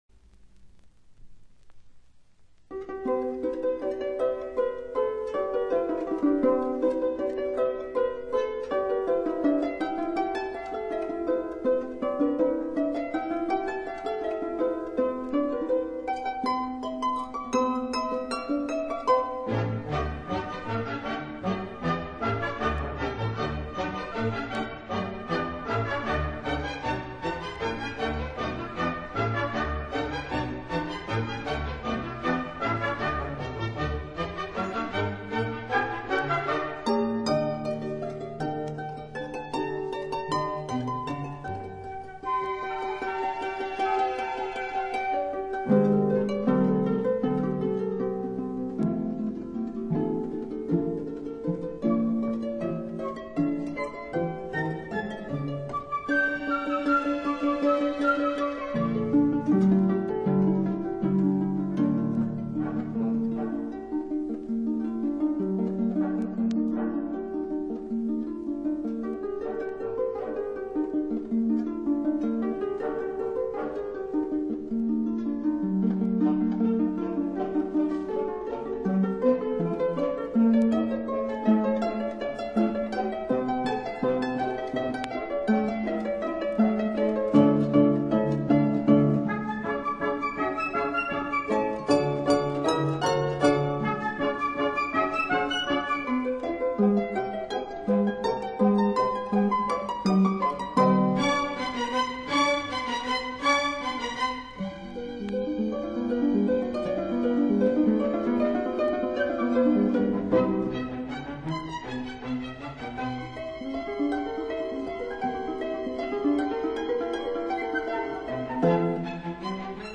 (Arranged for Harp by the Composer)
마지막 이 악장에는 궁정의 우아한 분위기가 흐른다.
Harp